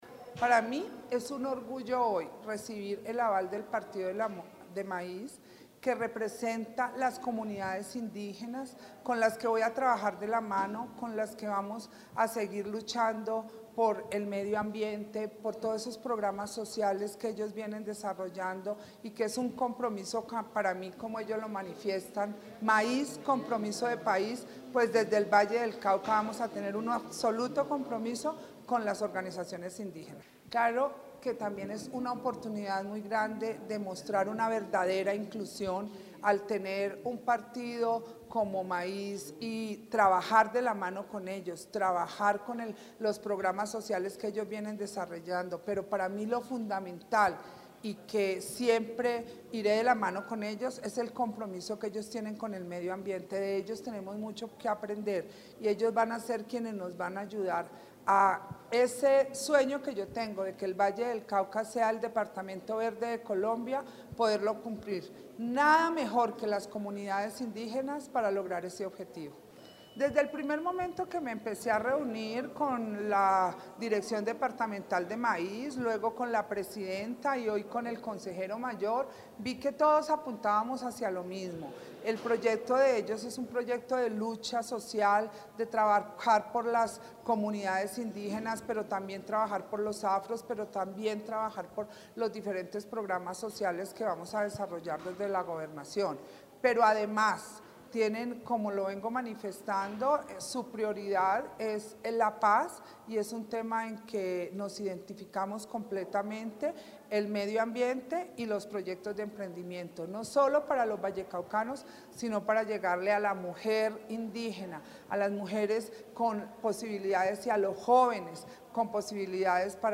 Declaraciones Clara Luz Roldán